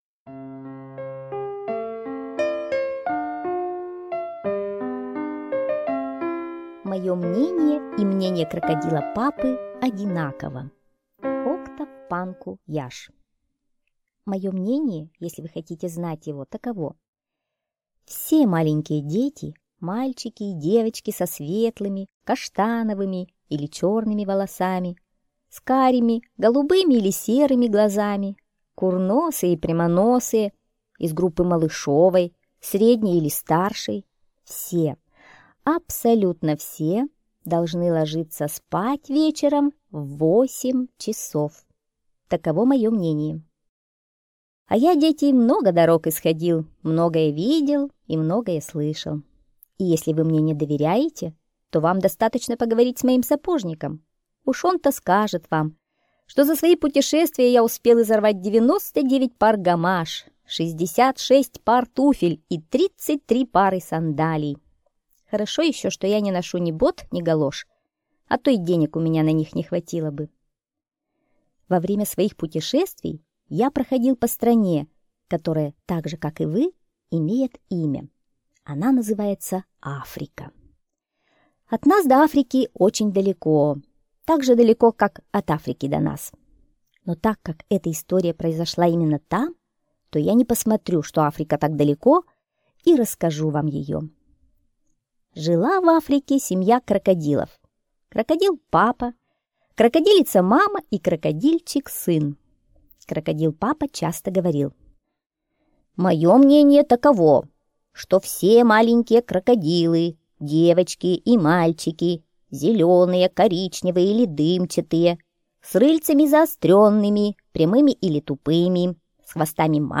Мое мнение и мнение крокодила-папы одинаково - аудиосказка Панку-Яшь О. В Африке Крокодильчик-сын не ложился спать в восемь часов вечера...